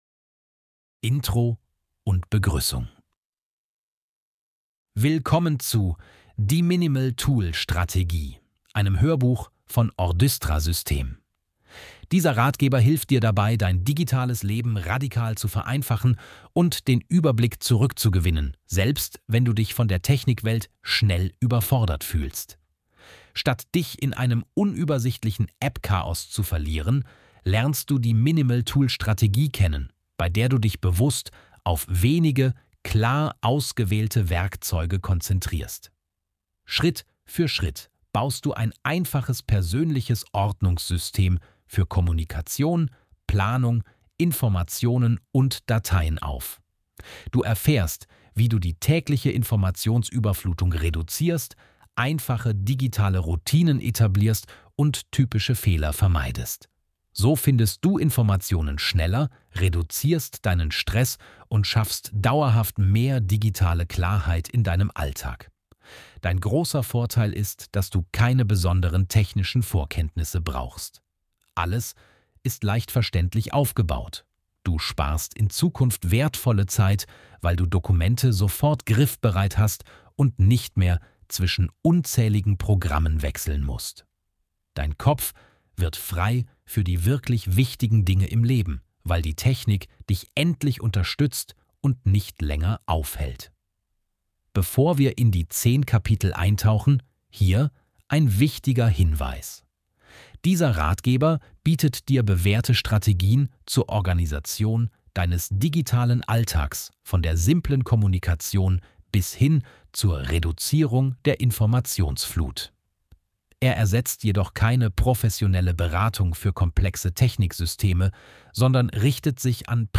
Wenn du deine digitale Überforderung stoppen möchtest, ist dieses Hörbuch genau richtig für dich.
Ordystra-Band-4-die-minimal-tool-strategie-kapitel-00-intro-und-begruessung.mp3